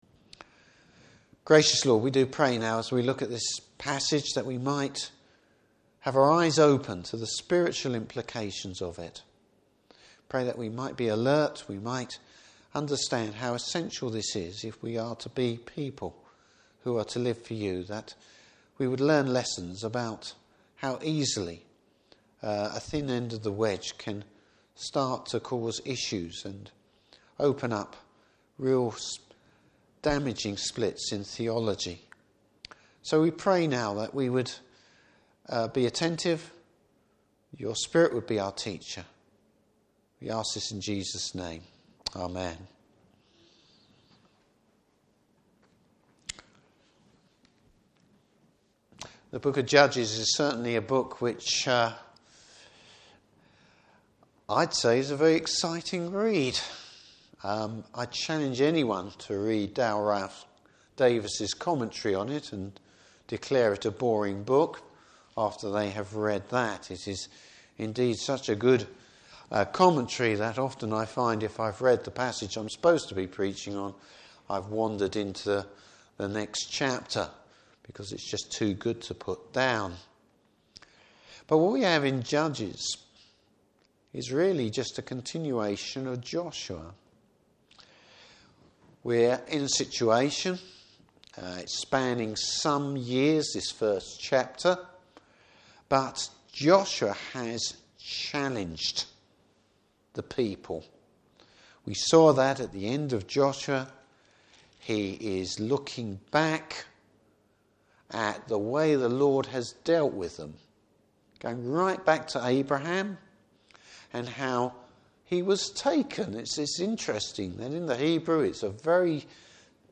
Service Type: Evening Service Bible Text: Judges 1.